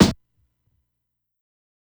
SNARE_MOMENT.wav